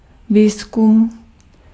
Ääntäminen
France: IPA: /ʁə.ʃɛʁʃ/